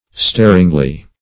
staringly - definition of staringly - synonyms, pronunciation, spelling from Free Dictionary Search Result for " staringly" : The Collaborative International Dictionary of English v.0.48: Staringly \Star"ing*ly\ (st[^a]r"[i^]ng*l[y^]), adv.